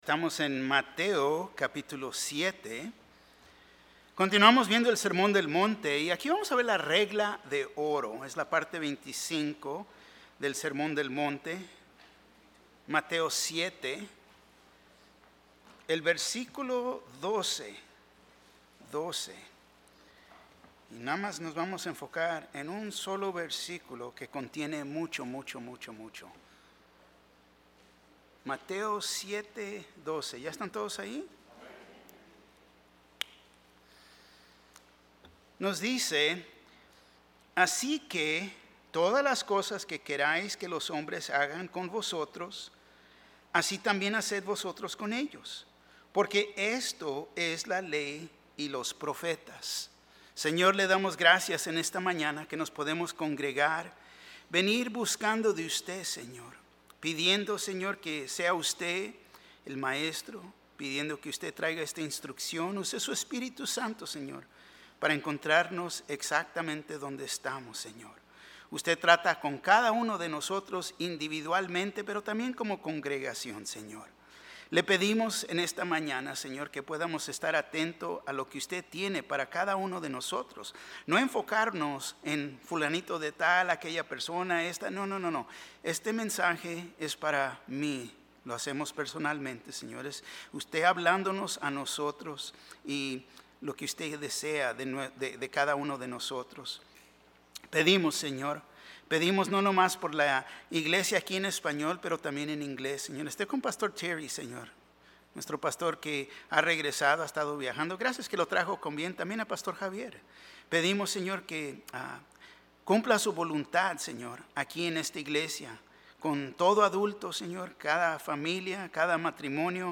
Un mensaje de la serie "Estudios Tématicos." ¿Te Has Postrado Ante El Rey Y Has Doblado Tu Rodilla Ante Jesús?